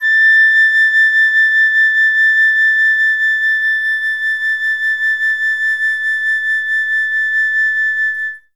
51c-flt12-A5.wav